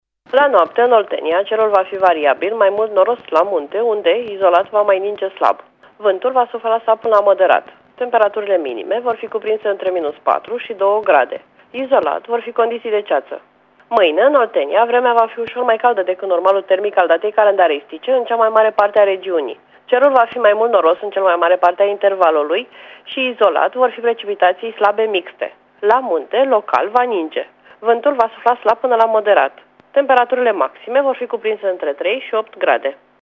Prognoza meteo 29/30 decembrie (audio)